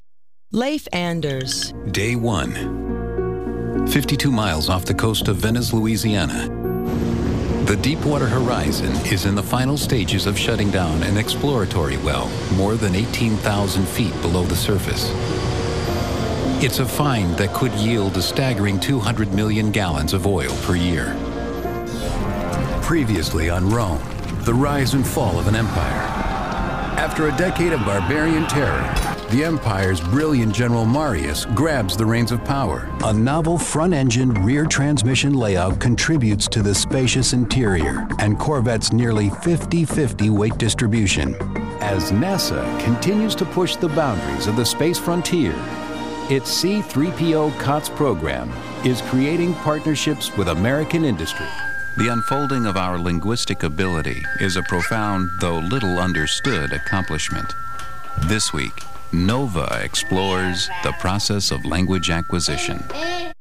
Male VOs
Listen/Download – Narration